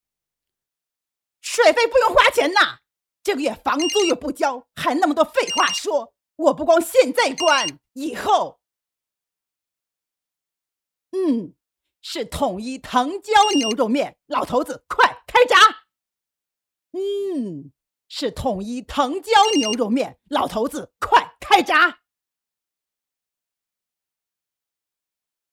女国145_动画_模仿_模仿包租婆音.mp3